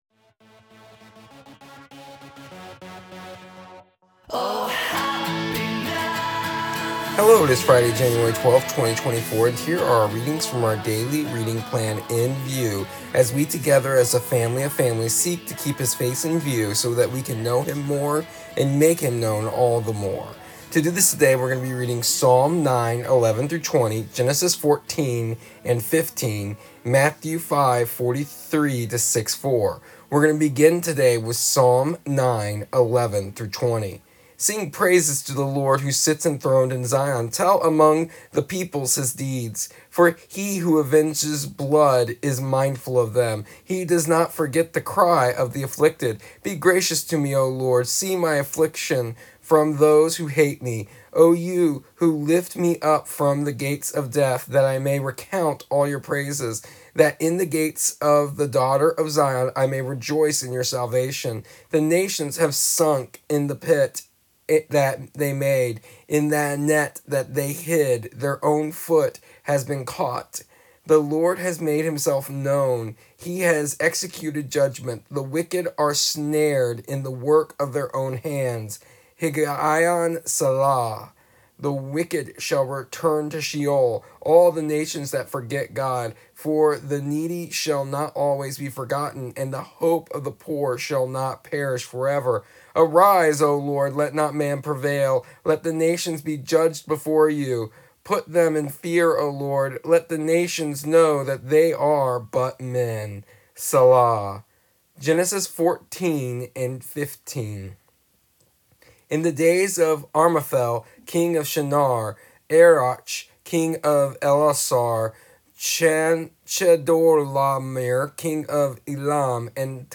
Here is the audio version of our daily readings from our daily reading plan “Keeping His Face in View” for January 12th, 2024.